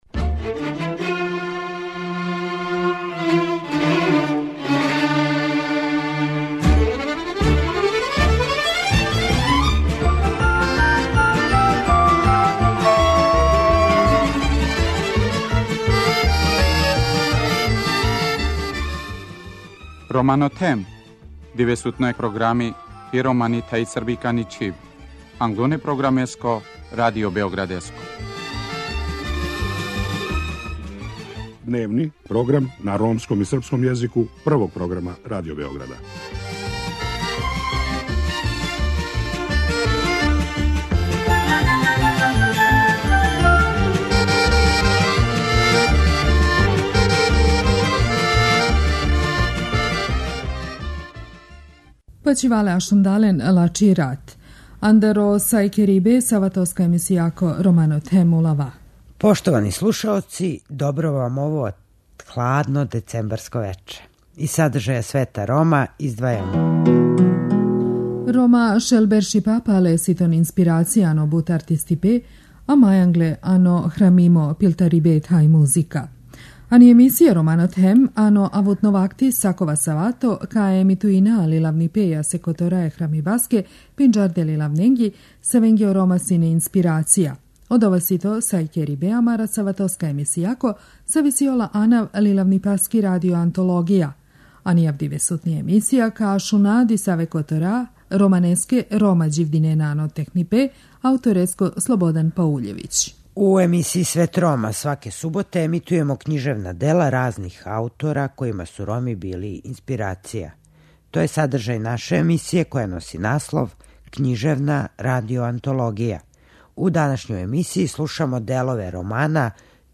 У данашњој емисији слушамо делове романа "Цигани живе у миру" аутора Слободана Пауљевића.